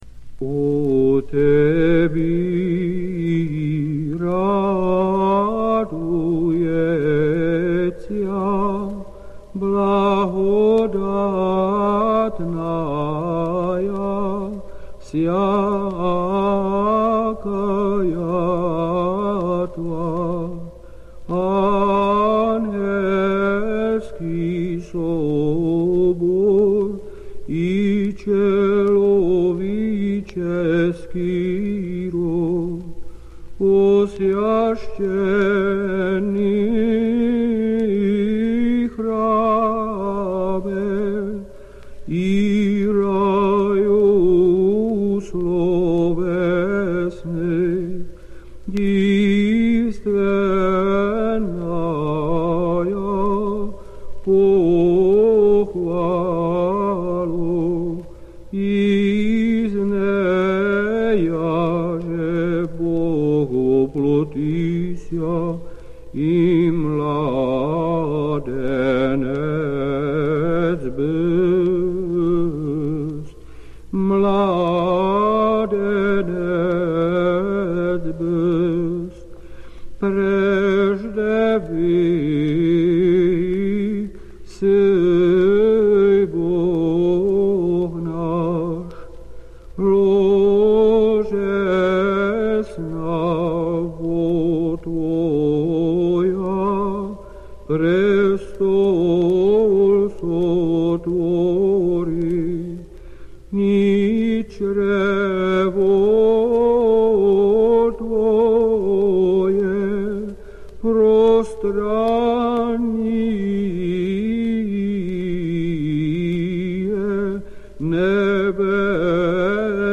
Note the key change to G, so that the melody begins on la and ends on ti. This melody is quite old, and comes from the Znammeny chant tradition.